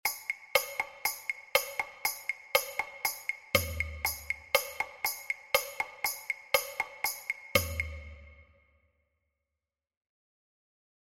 Cuando comenzamos a superponer diferentes líneas rítmicas se genera la polirritmia, entendida como un sistema rítmico resultante de la combinación de 2 o más ritmos ejecutados simultáneamente, creando un nuevo ritmo más dinámico y complejo.
Escuchamos como suena la polirritmia:
Base rítmica completa
OBtnExZ8UqX_Base-ritmica-completa.mp3